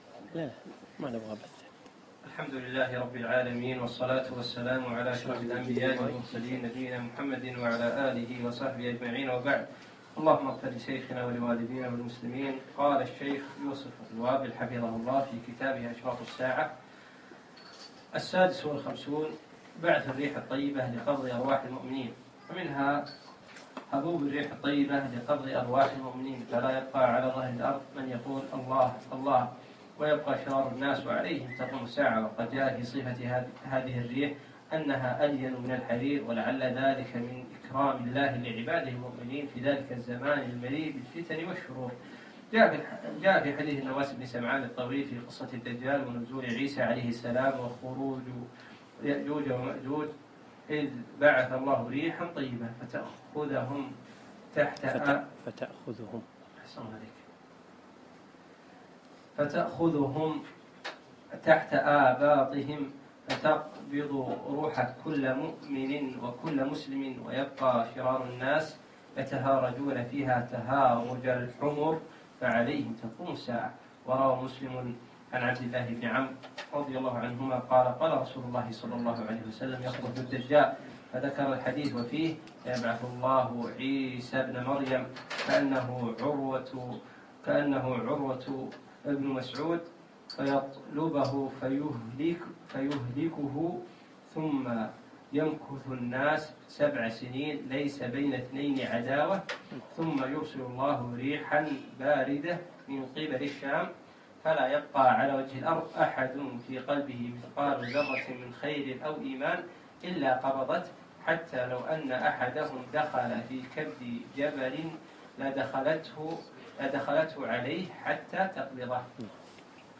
الدرس الثالث عشر: من الشرط 56 بعث الريح الطيبة إلى تتابع ظهور الأشراط الكبرى